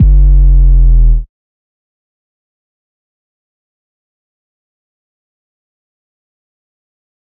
808s
DMV3_808 1.wav